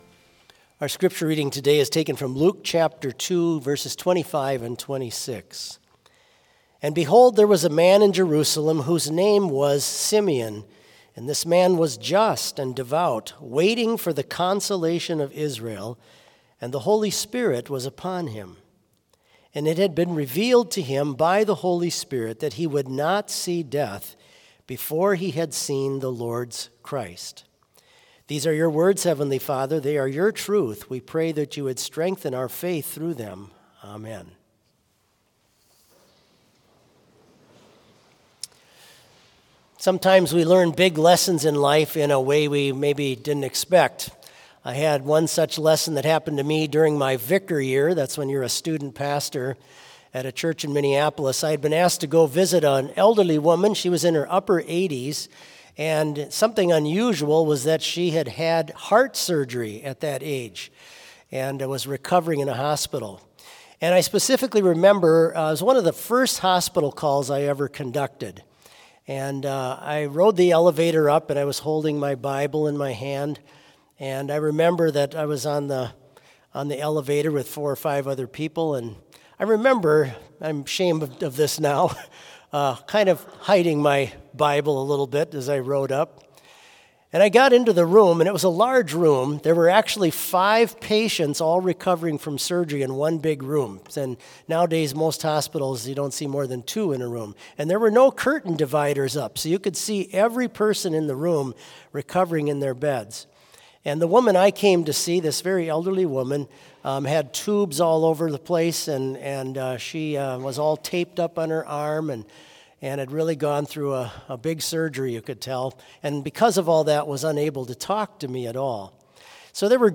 Complete service audio for Chapel - November 30, 2022
Watch Listen Complete Service Audio file: Complete Service Sermon Only Audio file: Sermon Only Order of Service Prelude Psalmody 25 P: Unto You, O Lord, I lift up my soul.